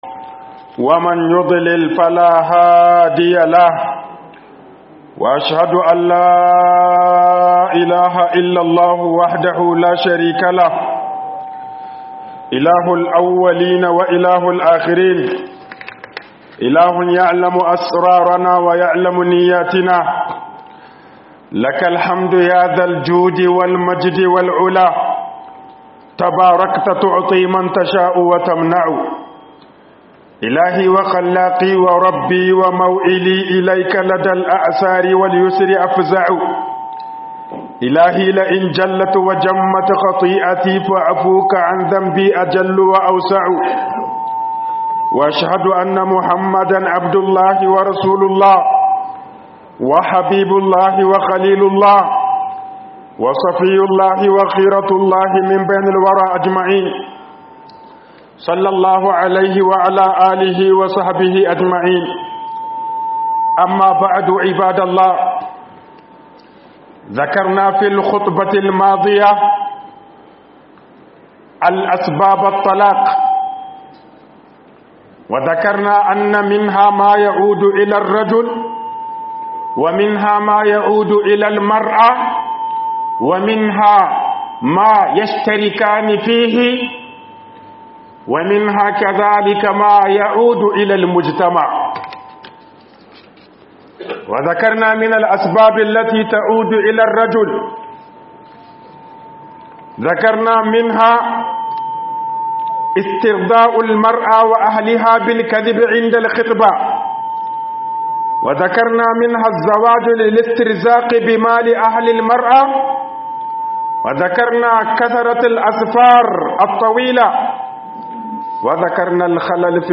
Dalilan Sakin Aure A Bangaren Maza 02 - HUƊUBOBIN JUMA'A